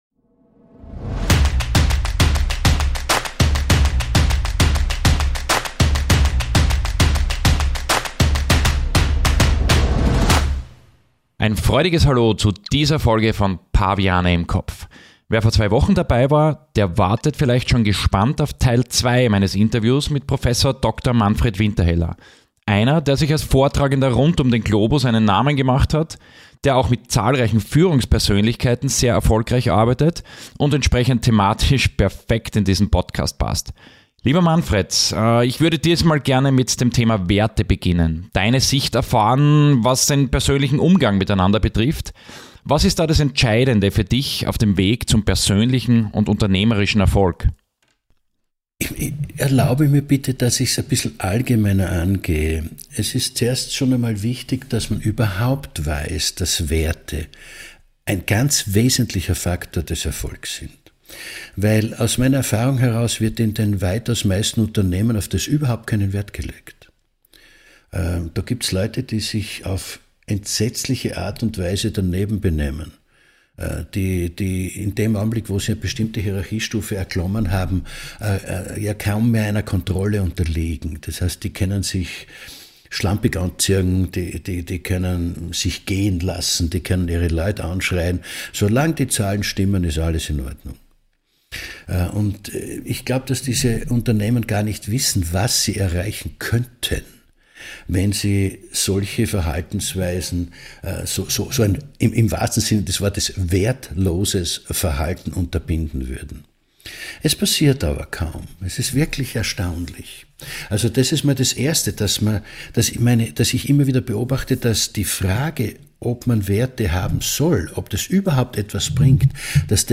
Der zweite Teil meines tiefgehenden Gesprächs